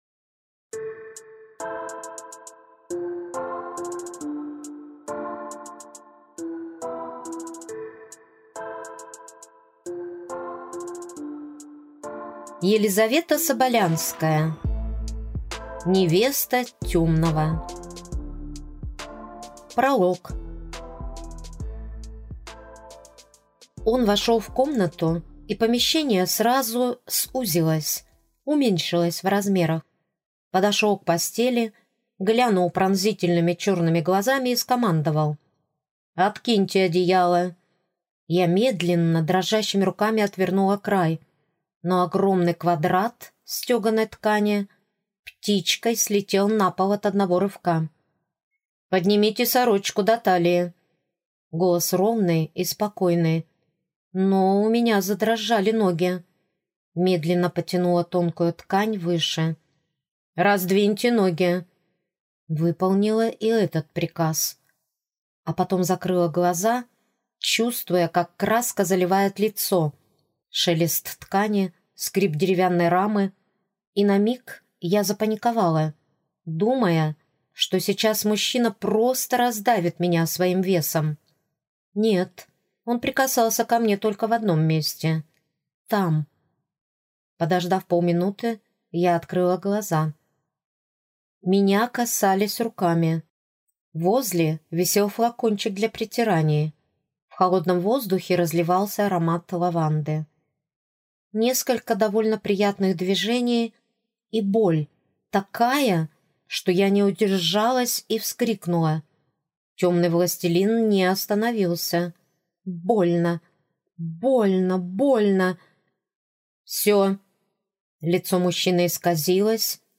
Аудиокнига Невеста Темного | Библиотека аудиокниг